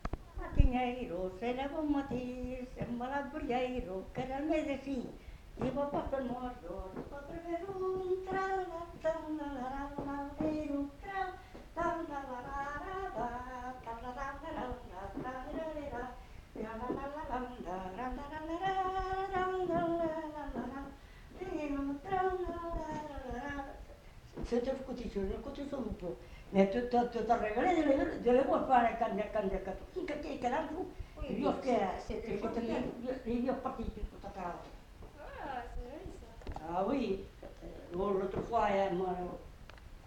Scottish-double (fredonné)
Genre : chant
Effectif : 1
Type de voix : voix d'homme
Production du son : fredonné
Danse : scottish double